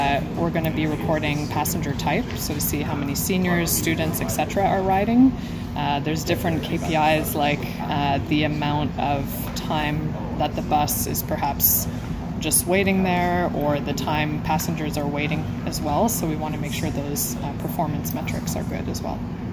At a press conference held at Algonquin College